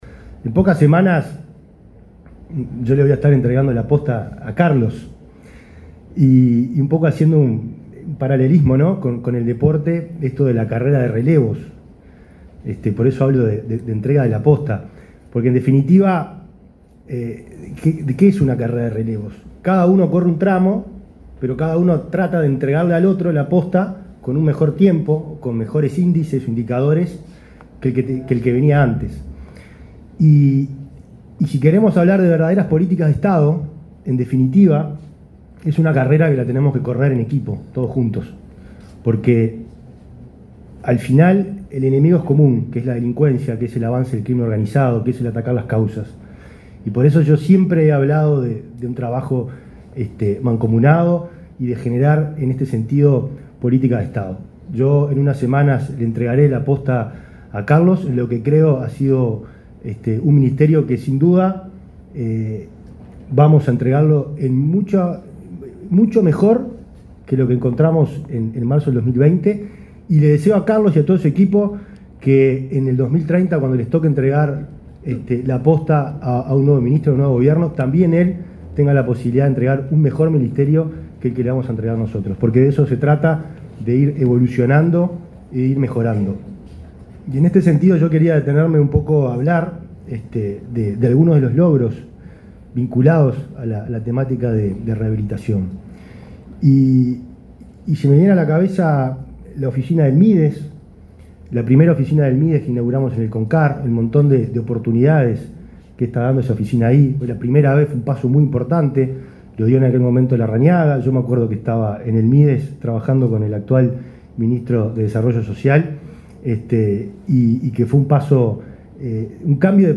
Palabras del ministro del Interior, Nicolás Martinelli